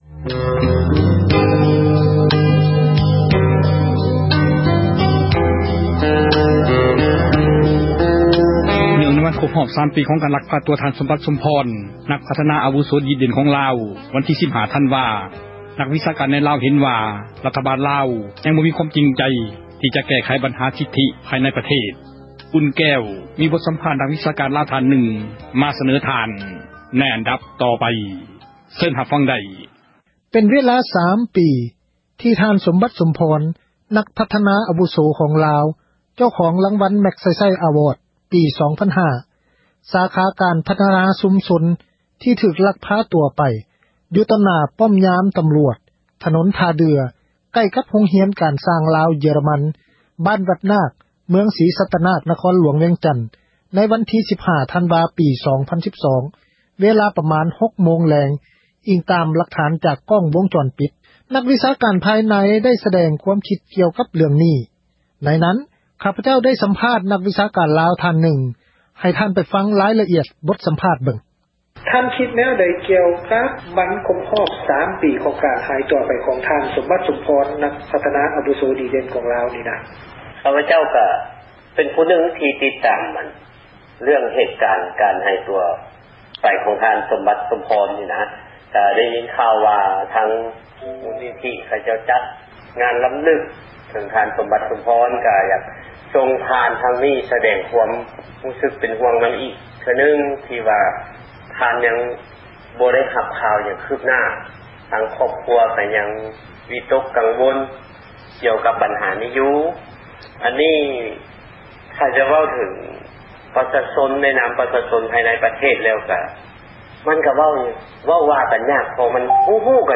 ສໍາພາດປັນຍາຊົນເຣື້ອງ ສົມບັດ